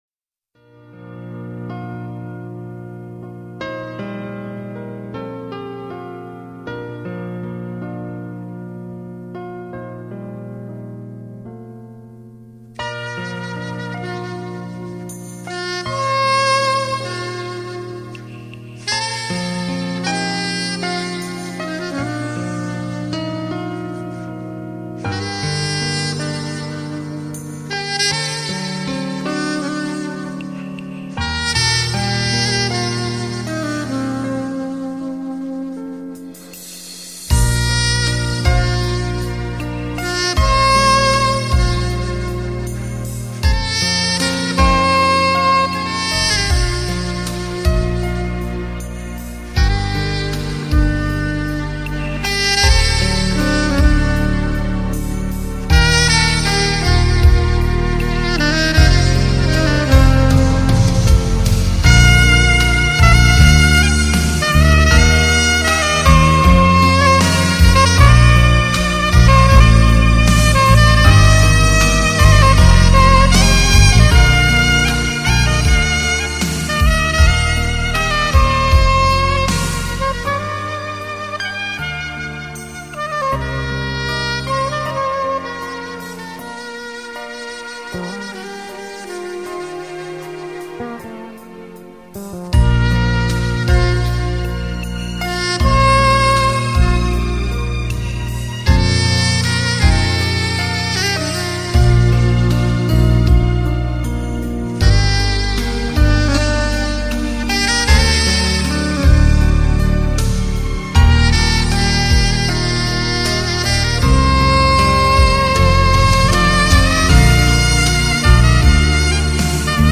萨克斯的味道，从离不开质感和舒畅。经典的萨克斯名曲，配以温柔的演绎，不得不听的蓝调情怀。
车旅必听HIFI萨克斯名曲：